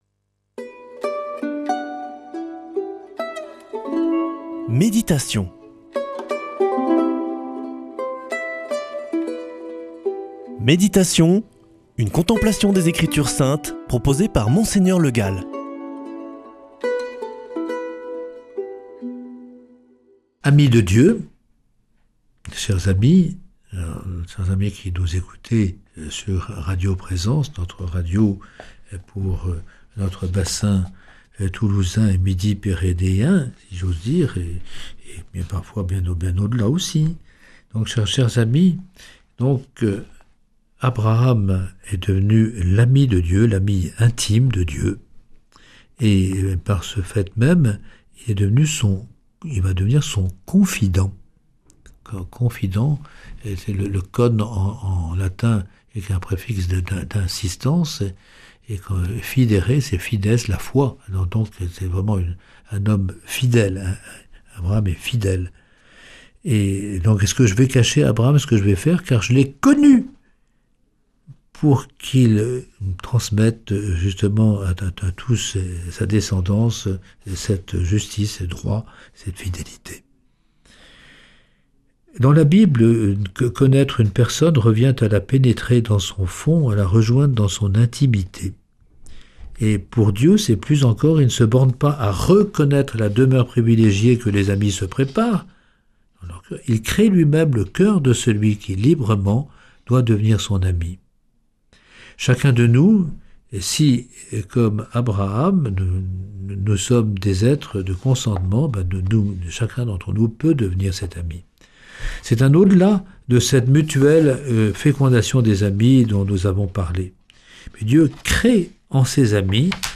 Méditation avec Mgr Le Gall
Présentateur